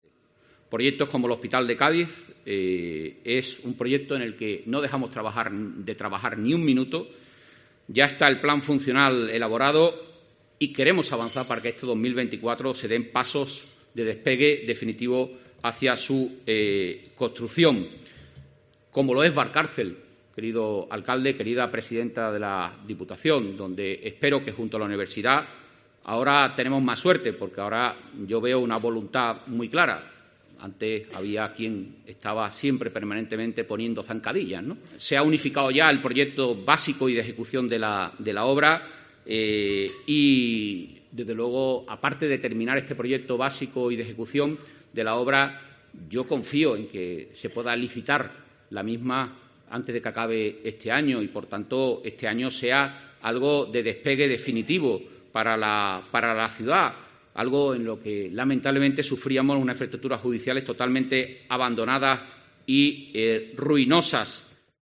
AUDIO: Antonio Sanz, consejero de la Presidencia de la Junta de Andalucía, habla de los grandes proyectos para Cádiz